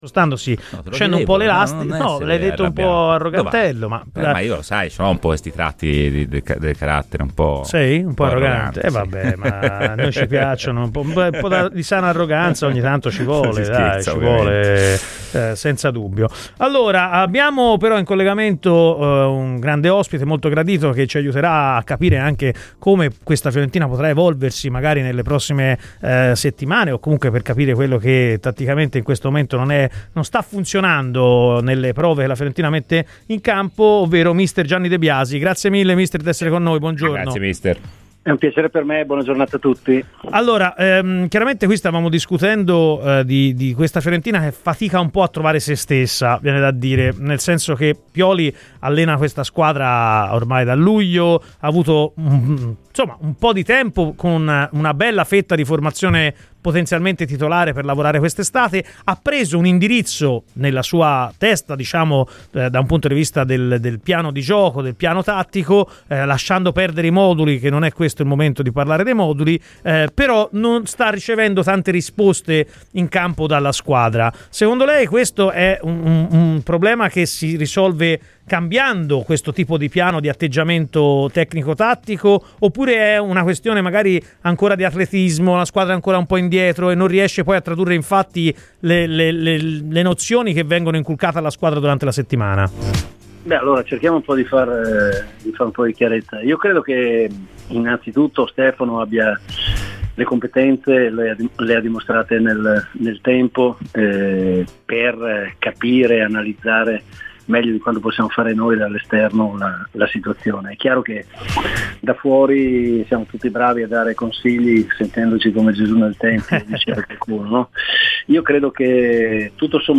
Nella mattinata di Radio FirenzeViola, spazio a Gianni De Biasi.